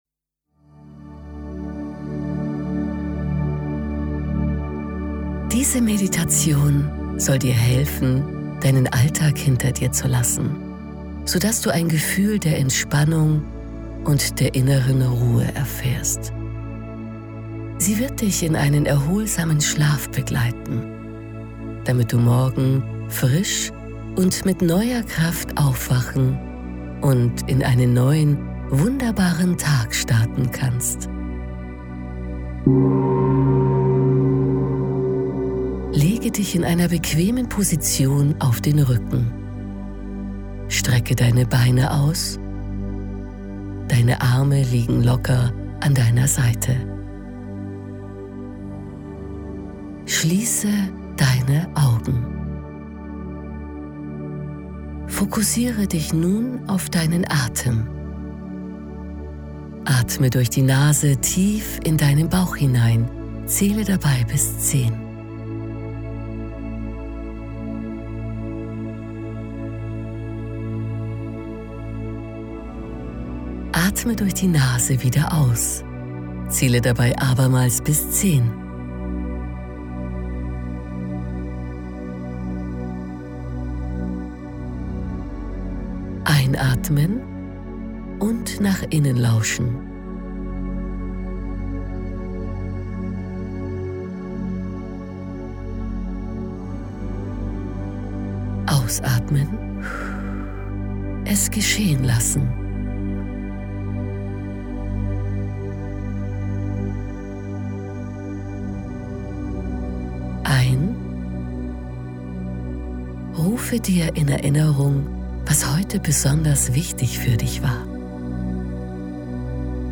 Meditation zum Einschlafen
tena-meditation-zum-einschlafen-de.mp3